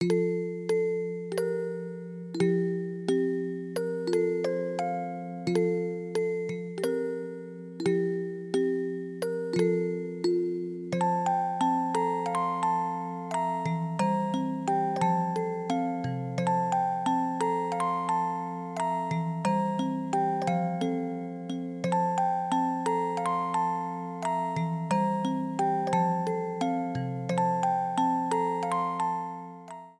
The music box